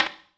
darkwatch/client/public/dice/sounds/surfaces/surface_wood_table1.mp3 at fbdd9c49eef77bbf81535a2e34f42a44c89d328b
surface_wood_table1.mp3